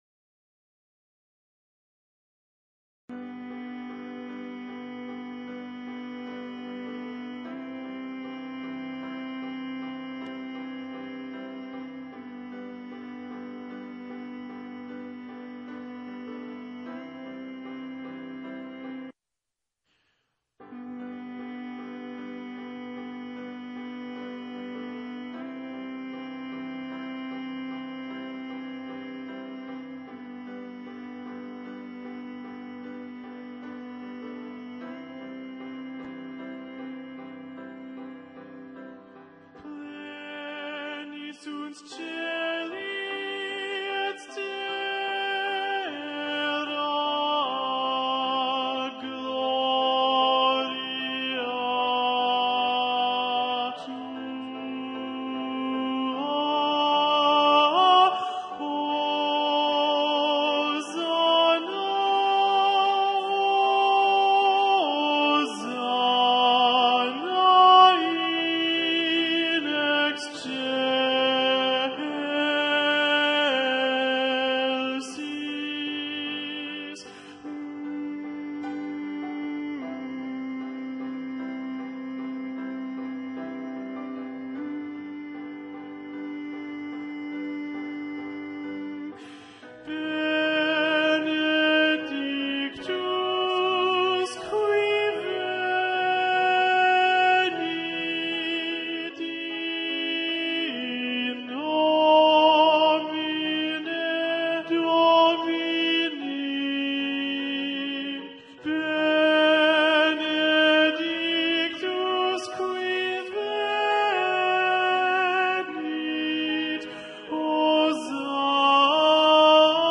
Répétition SATB par voix,  (mp3 sur le site, accès direct)
Alto 2
Ground Alto 2 Solo.mp3